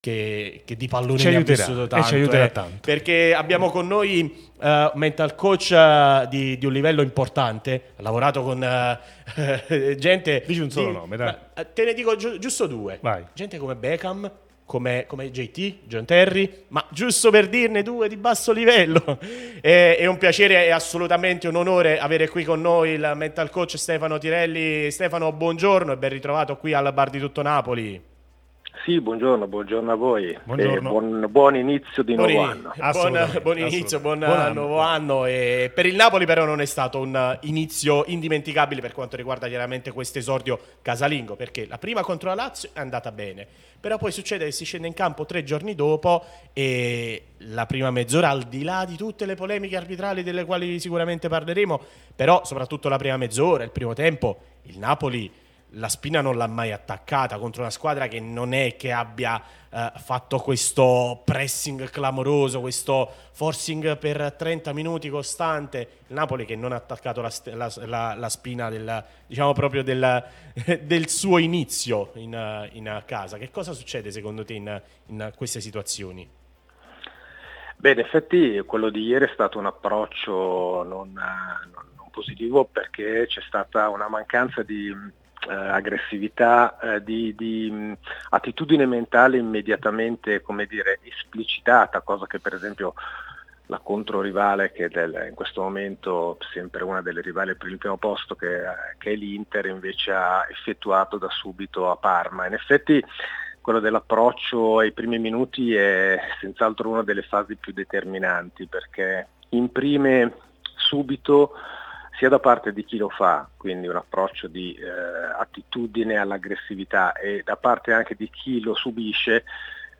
Radio TN